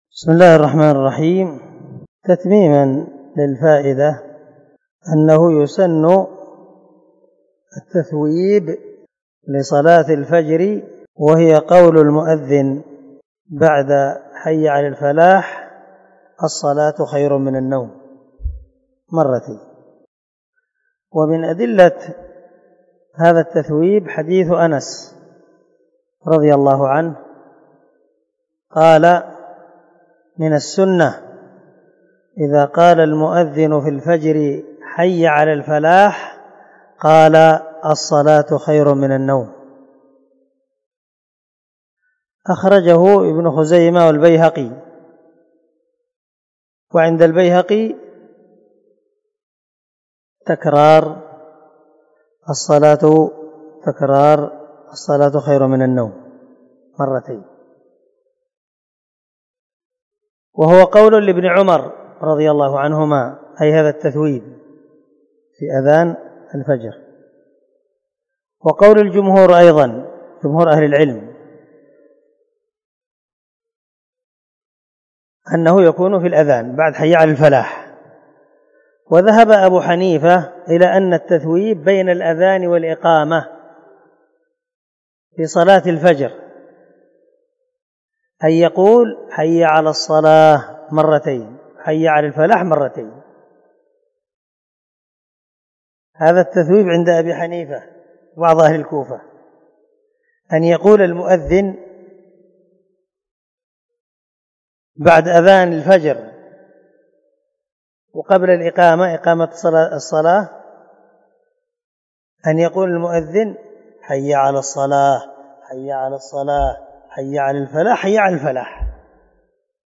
260الدرس 4 من شرح كتاب الصلاة تابع حديث رقم ( 379 ) من صحيح مسلم